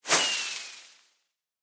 sounds / fireworks / launch1.ogg
launch1.ogg